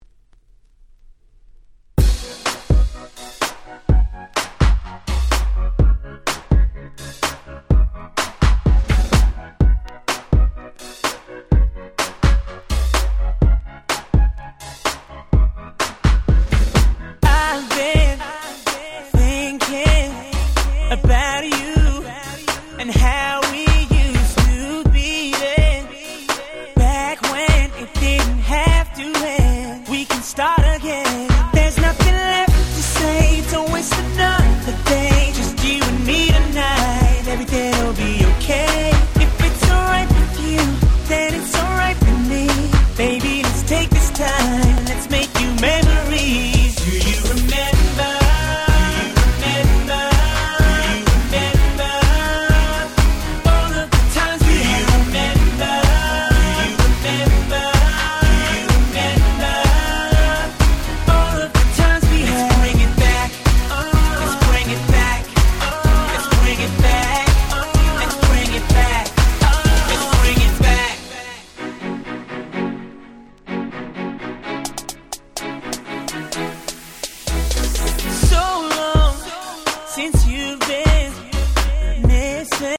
10' Super Hit R&B !!
超キャッチー！！